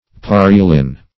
Search Result for " parillin" : The Collaborative International Dictionary of English v.0.48: Parillin \Pa*ril"lin\, n. [Shortened fr. sarsaparillin.]